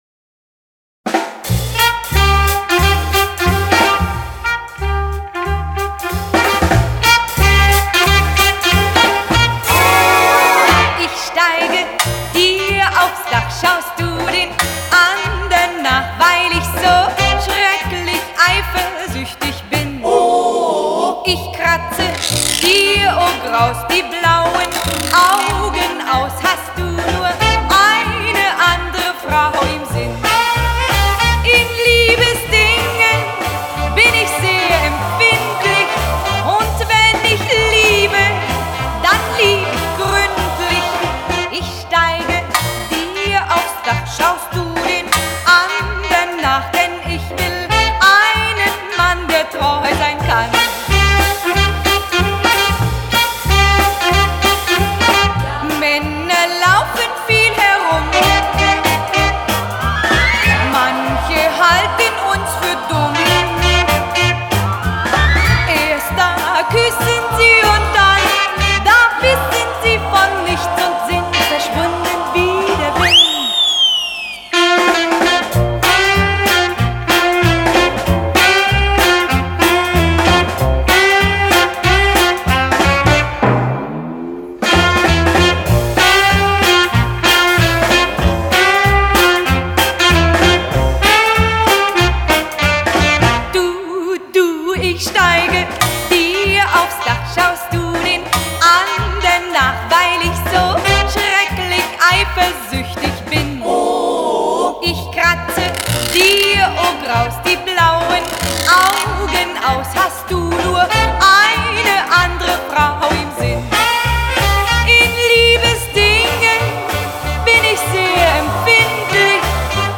фокстрот